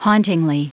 Prononciation du mot : hauntingly
hauntingly.wav